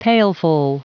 Prononciation du mot pailful en anglais (fichier audio)